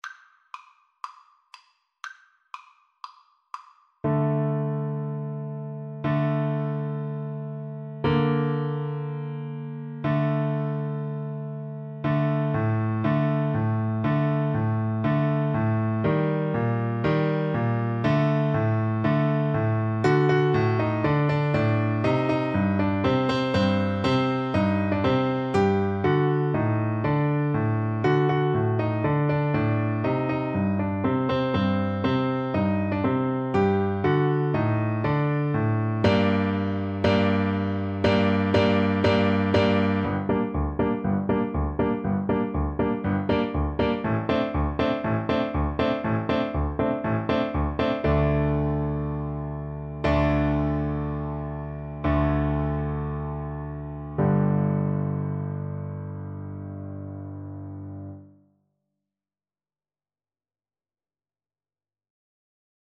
Free Sheet music for Piano Four Hands (Piano Duet)
"Hava Nagila" or "Let us rejoice" is a Jewish traditional folk song in Hebrew, that has become a staple of band performers at Jewish weddings and Bar/Bat Mitzvahs.
Vivace (View more music marked Vivace)
4/4 (View more 4/4 Music)
Bb major (Sounding Pitch) (View more Bb major Music for Piano Duet )
Easy Level: Recommended for Beginners with some playing experience
Traditional (View more Traditional Piano Duet Music)